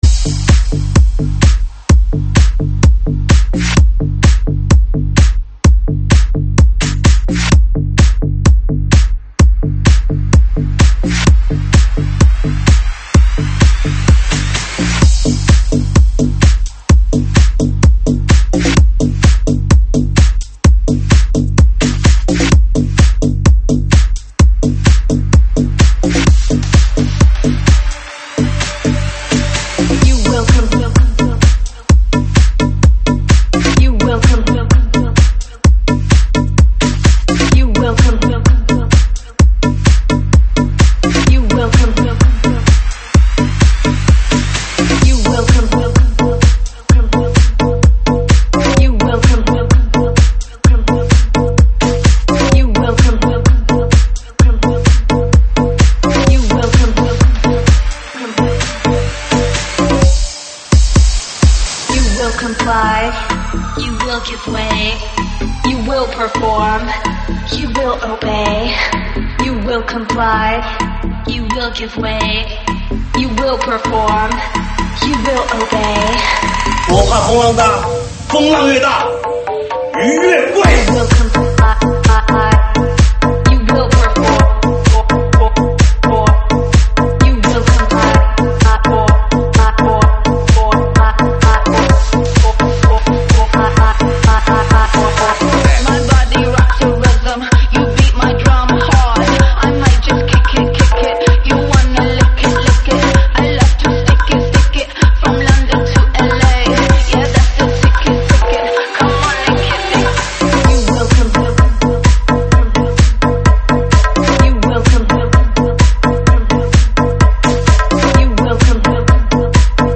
舞曲类别：慢摇舞曲